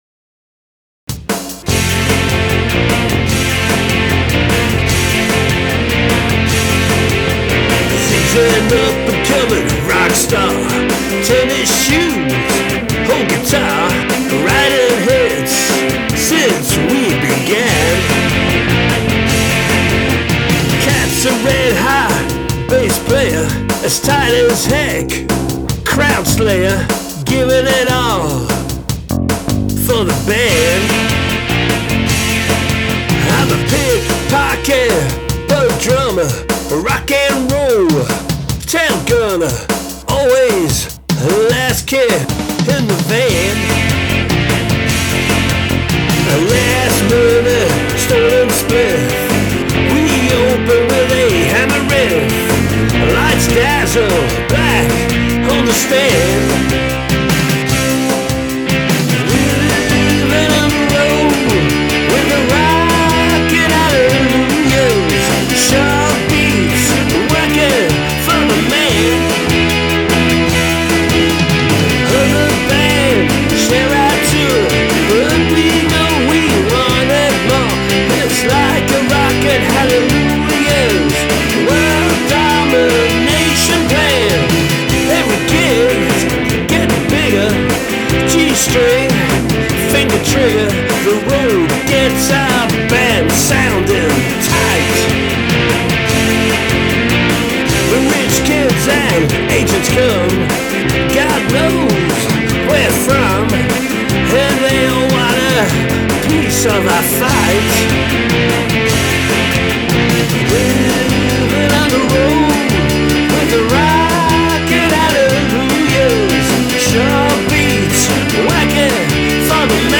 a great guitar riff